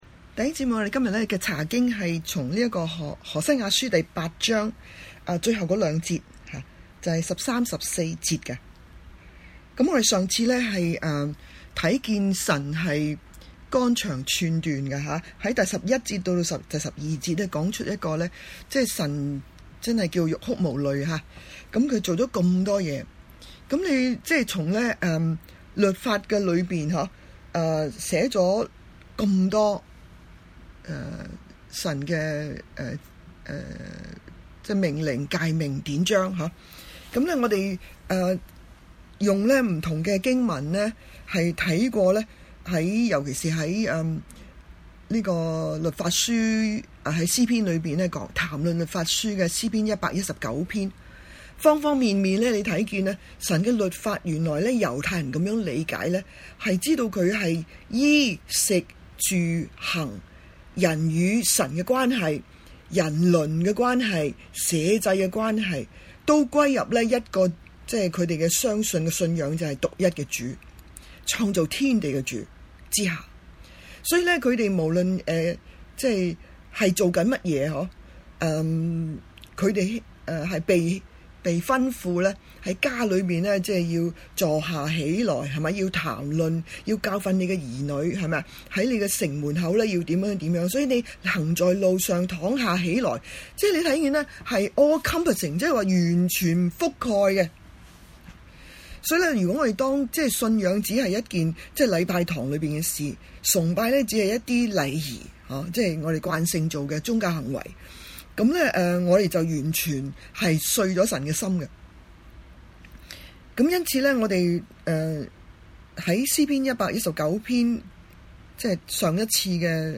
周五查經班 Friday Bible Study